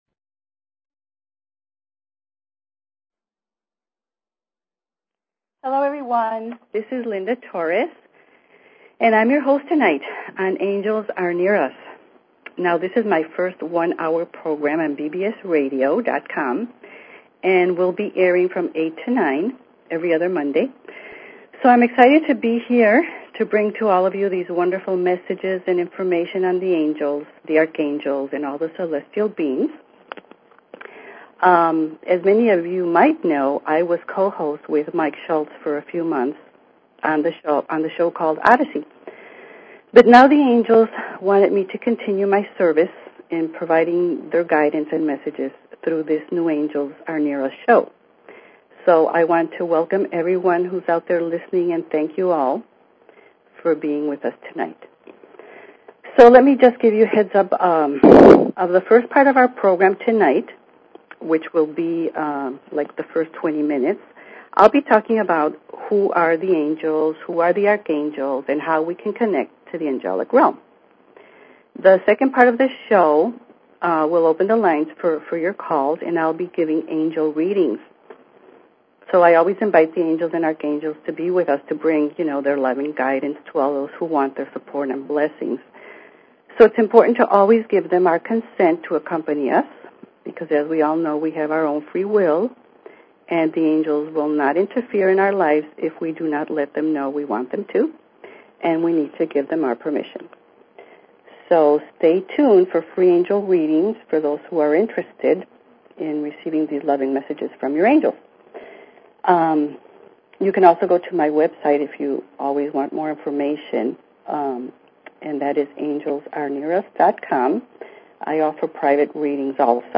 Talk Show Episode
The last 30 minutes of the show the phone lines will be open for questions and Angel readings.